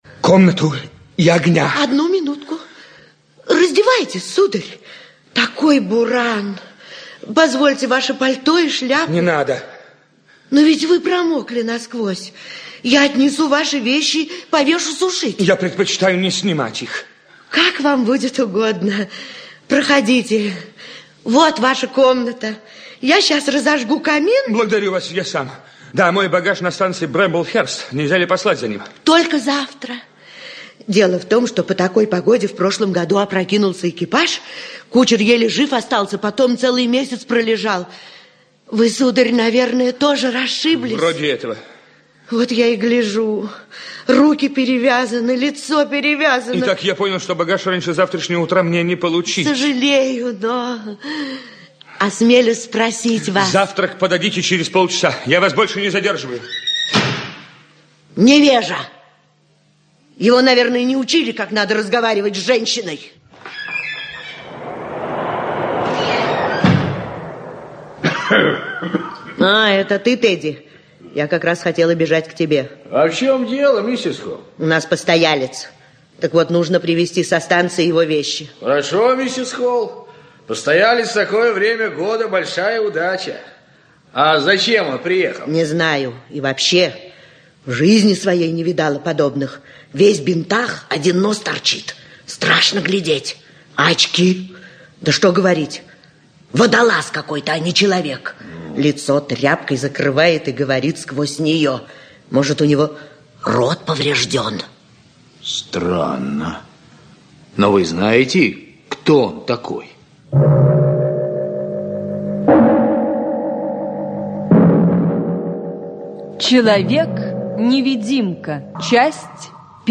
Аудиокнига Человек-невидимка (спектакль Гостелерадио) | Библиотека аудиокниг
Aудиокнига Человек-невидимка (спектакль Гостелерадио) Автор Герберт Джордж Уэллс Читает аудиокнигу Актерский коллектив.